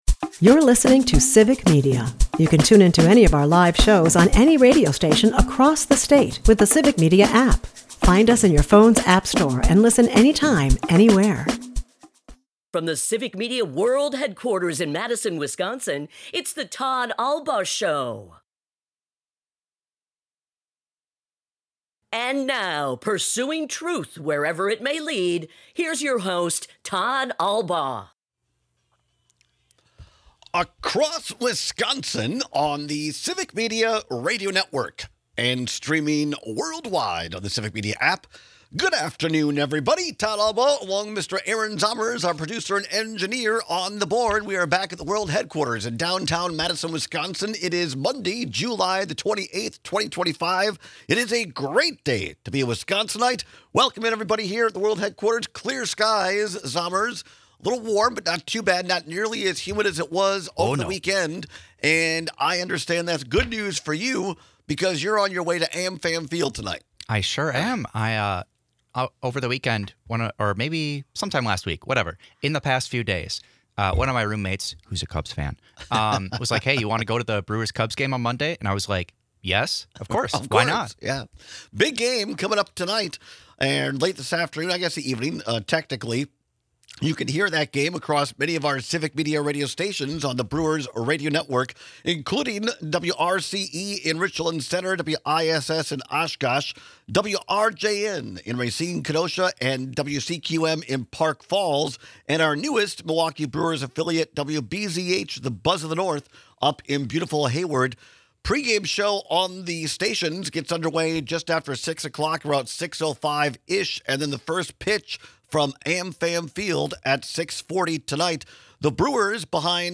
Over the weekend, we got a few updates on who is or is not running for Governor of Wisconsin next year. The first Democrat to announce a campaign is Lieutenant Governor Sara Rodriguez, and she joins us live to share why she wants to be our Governor.